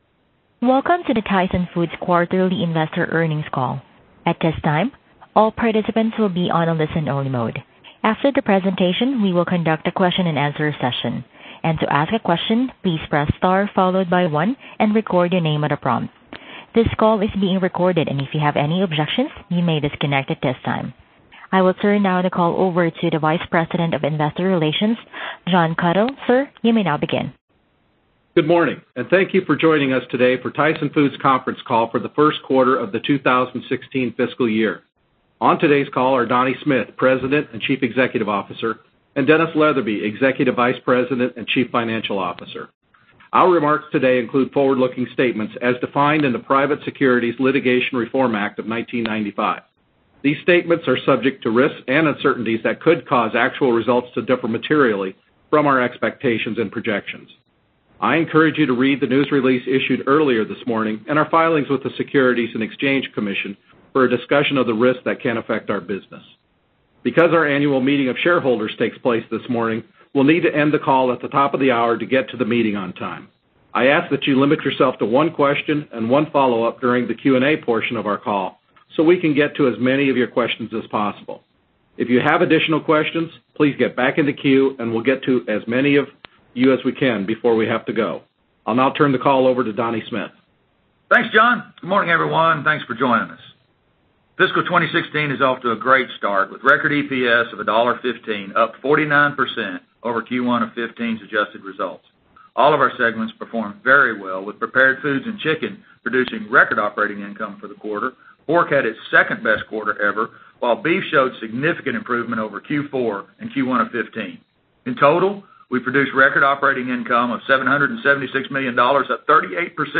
Tyson Foods Inc. - Q1 2016 Tyson Foods Earnings Conference Call